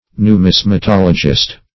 Numismatologist \Nu*mis`ma*tol"o*gist\, n.
numismatologist.mp3